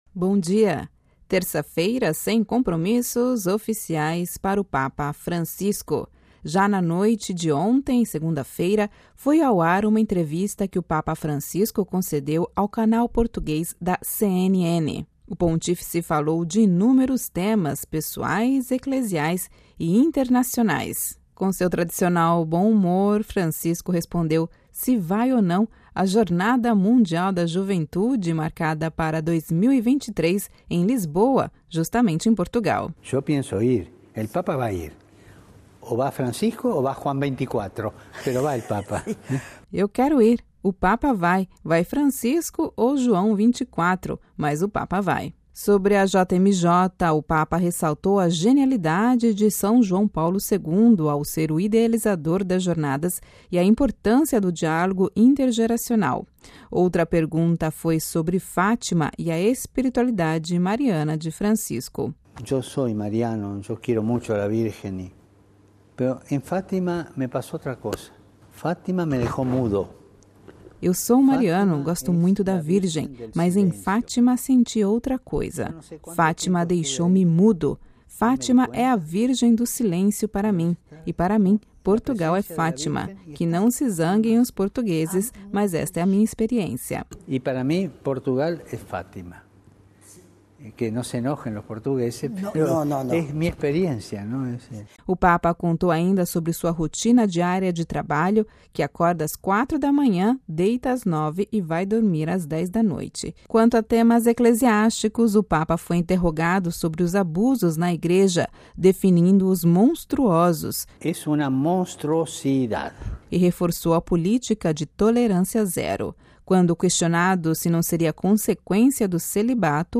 Ouça a reportagem completa com a voz do Papa Francisco